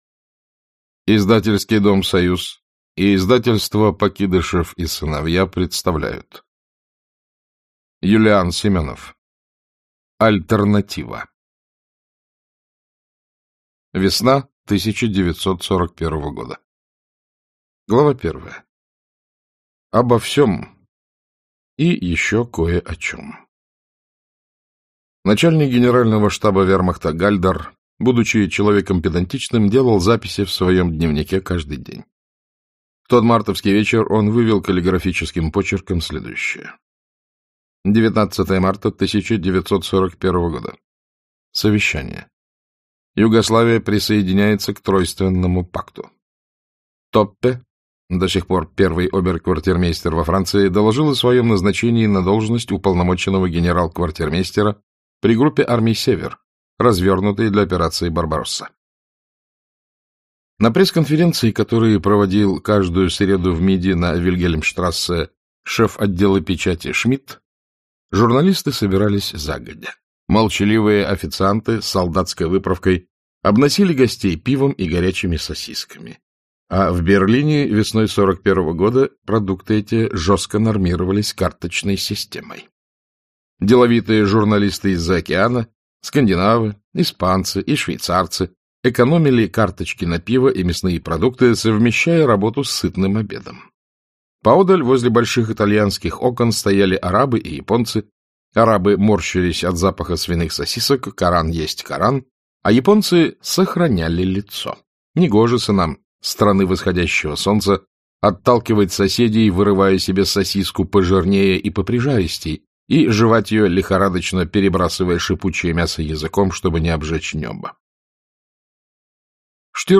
Аудиокнига Альтернатива | Библиотека аудиокниг